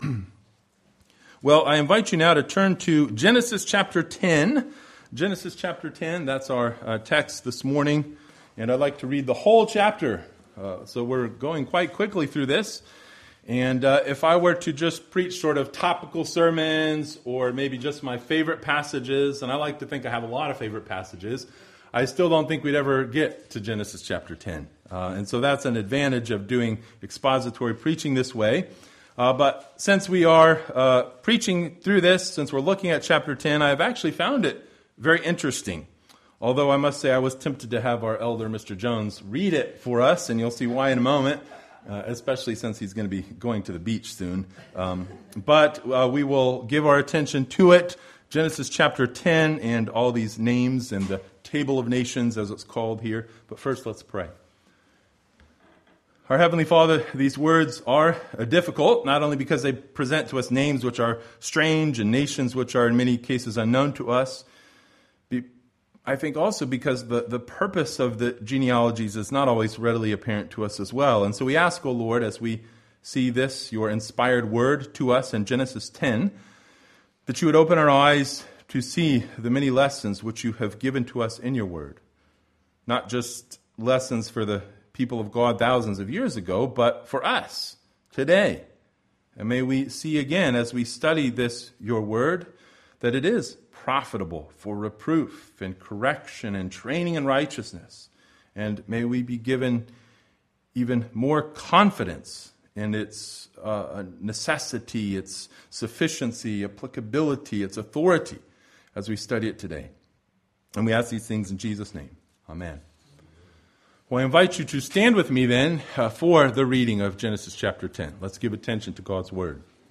Genesis 10 Service Type: Sunday Morning Bible Text